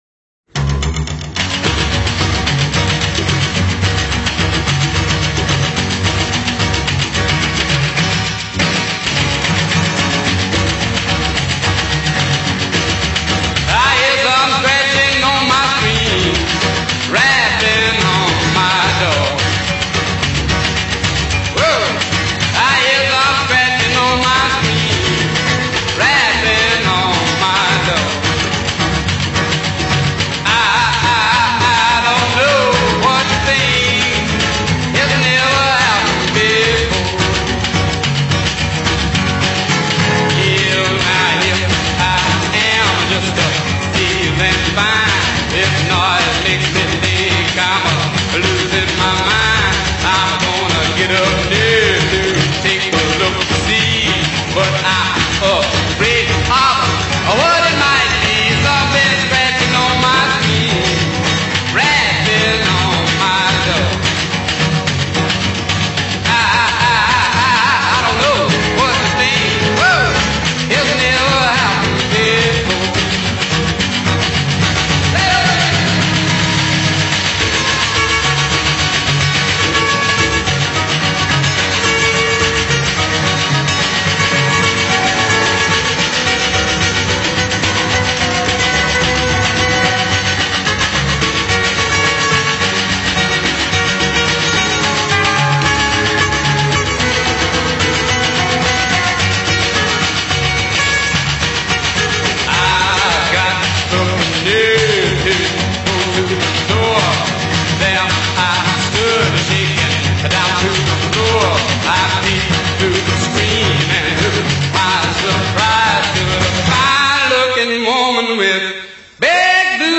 obscure 1950s Rockabilly